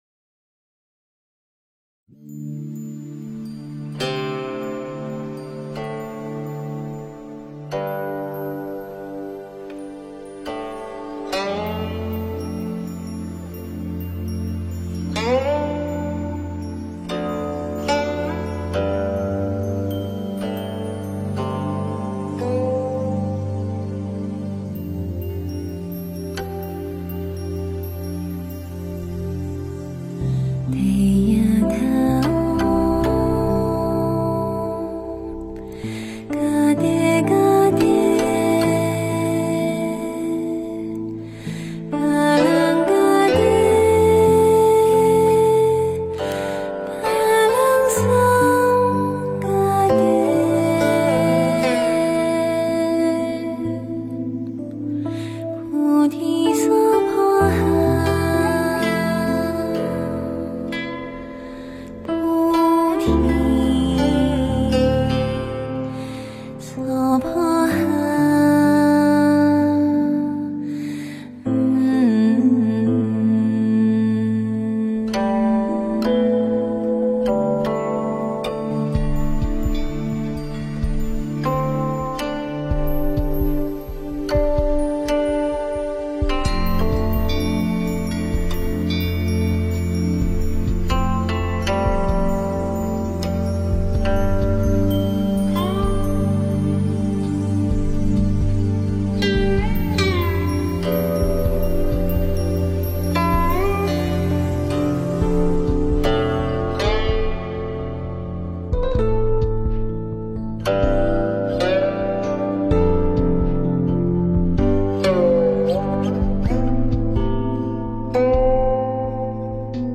标签: 佛音诵经佛教音乐